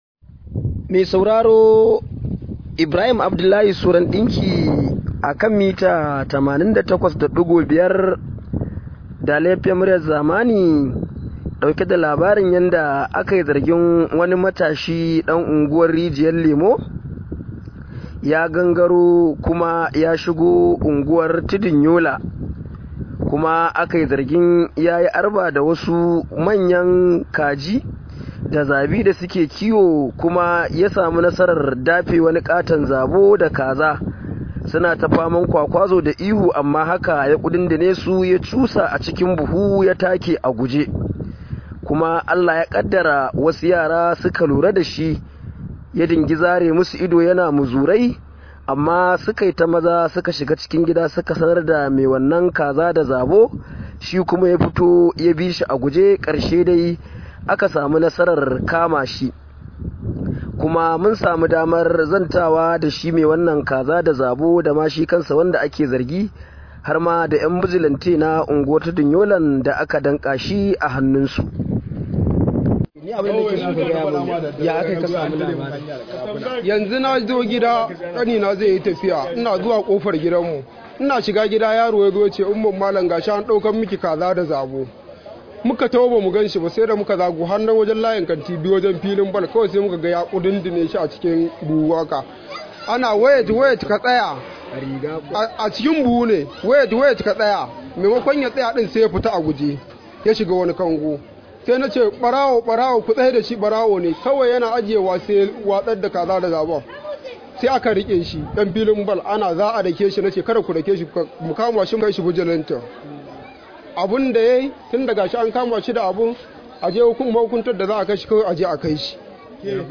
Rahoto: Na saci Zabo da Kaji domin samun kudin zuwa Abuja – Matashi